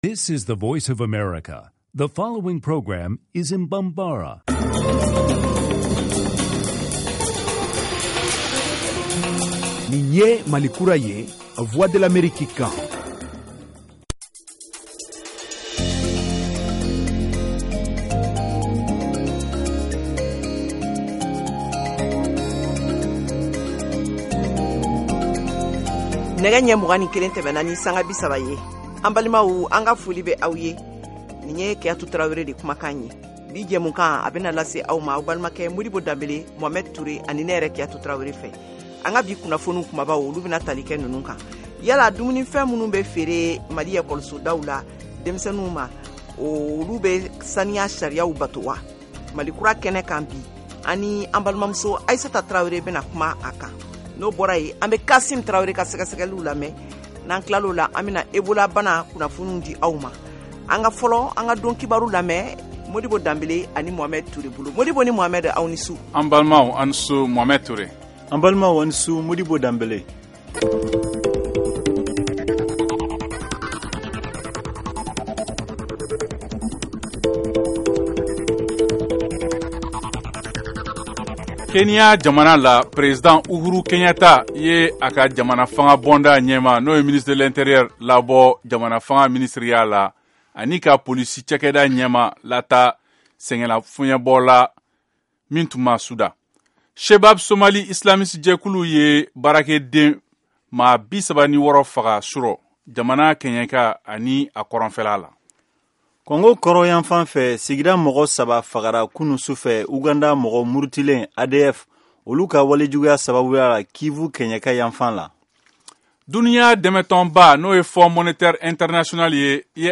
Emission quotidienne en langue bambara
en direct de Washington, DC, aux USA.